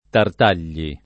[ tart # l’l’i ]